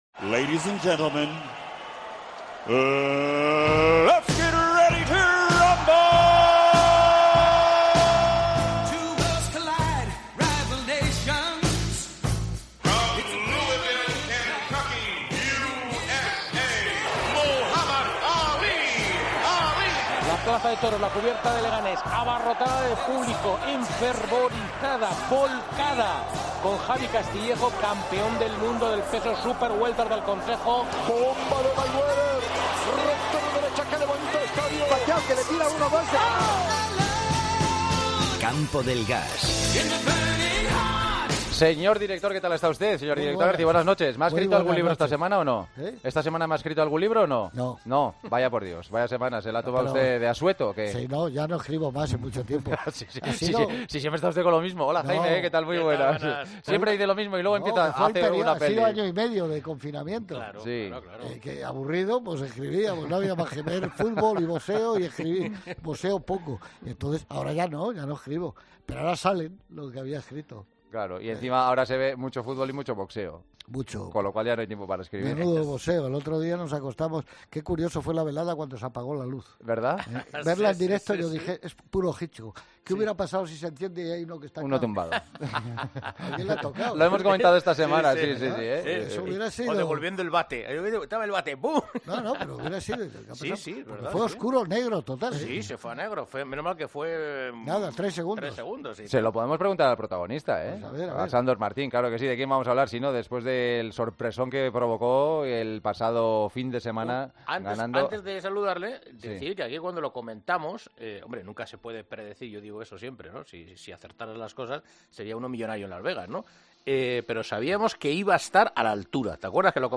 Entrevista a Sandor Martín tras su victoria contra Mikey García.